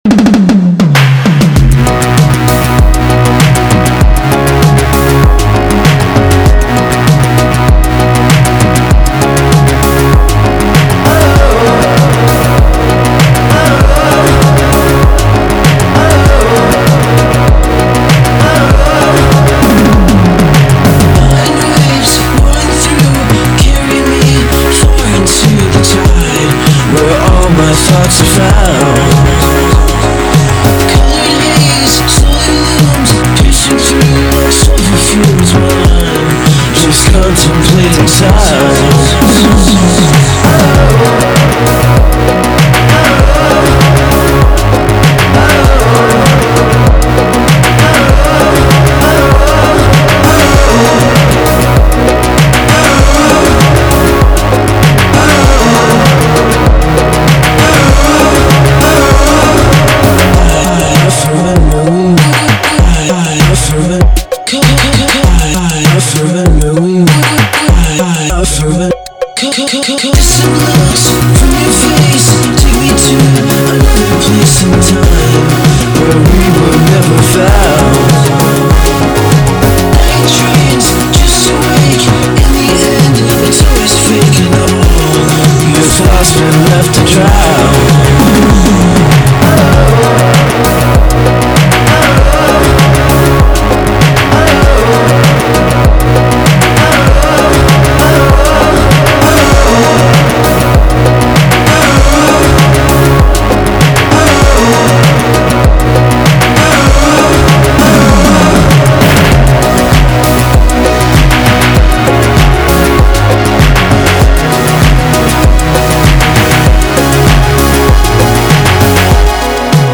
hot remix
With just a simple addition of the repeated call “Oh OH oh,”
catchy pop hook